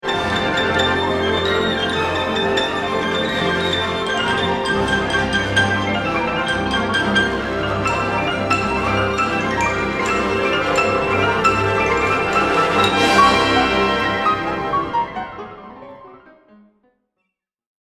for hyperpiano and orchestra